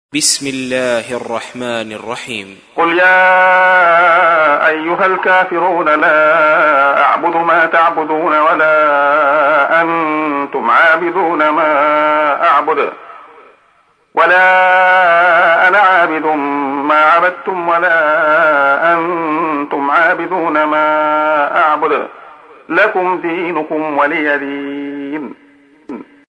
تحميل : 109. سورة الكافرون / القارئ عبد الله خياط / القرآن الكريم / موقع يا حسين